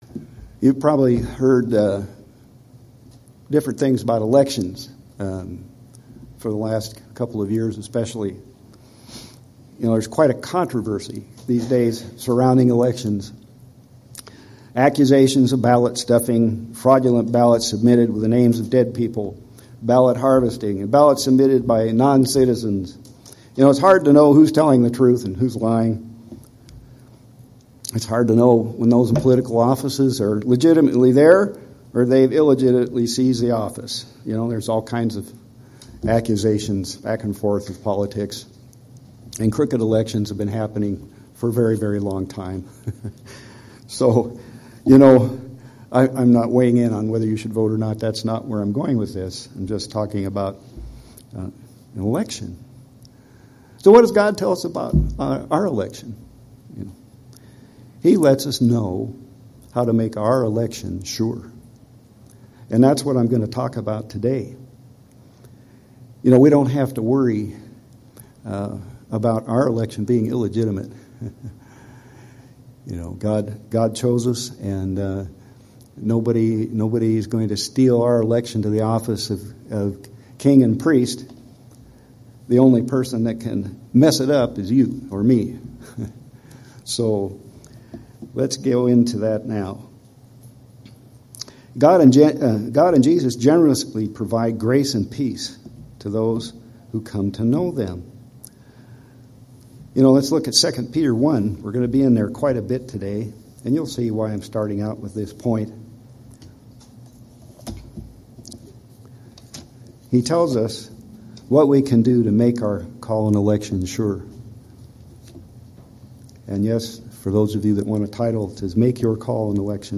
Sermons
Given in Central Oregon